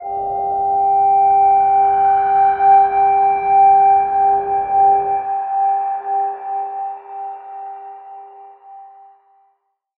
G_Crystal-G5-f.wav